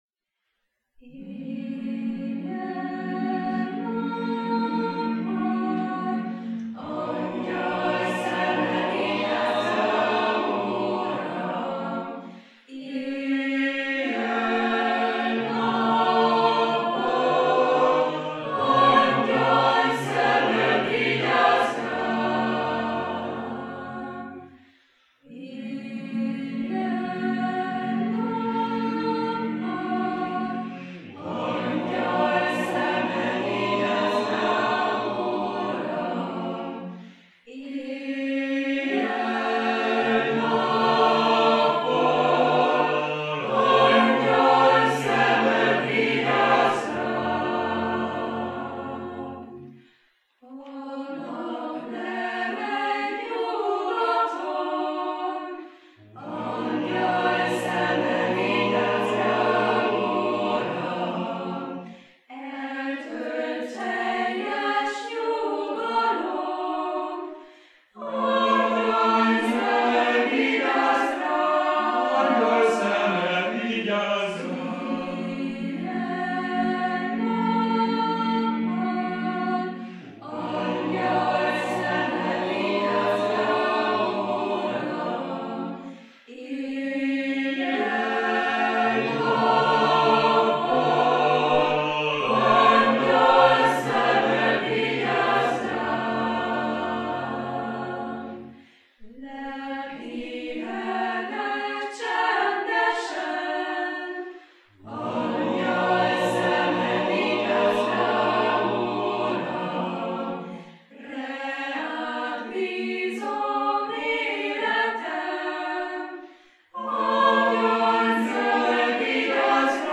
Dallam: Spirituálé.
Ez a spirituálé éppen ilyen: nem mond sokat szavaival, csak hitet tesz az Isten iránti bizalomról. De nyugodt lüktetése, egyszerű dallama és édes összhangzásai szinte simogatnak, álomba ringatnak.